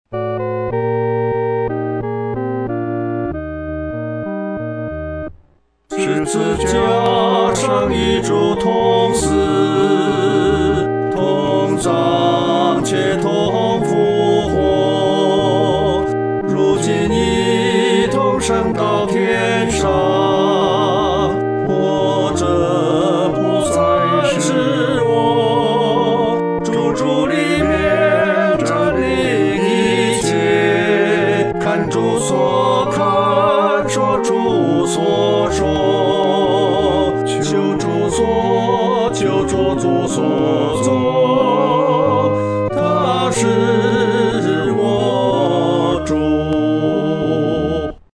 合唱（男二声部）